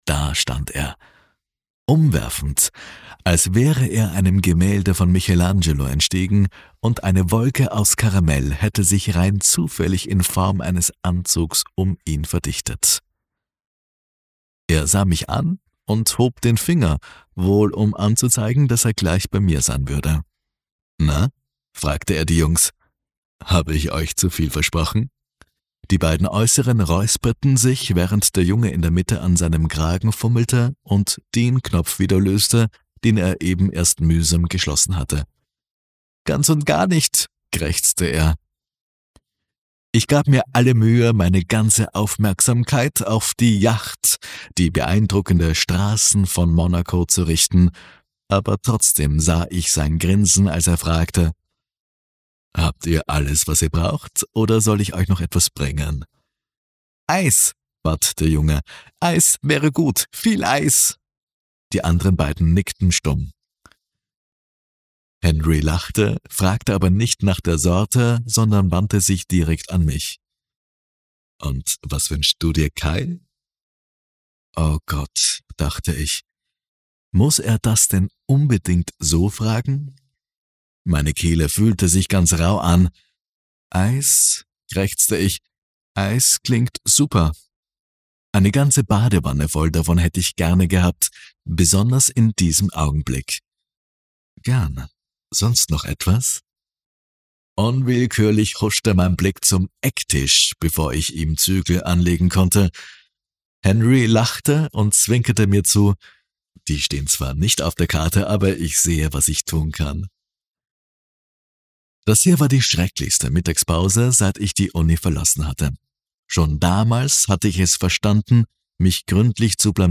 Hörbuch über audible: 2,75 €